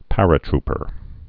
(părə-trpər)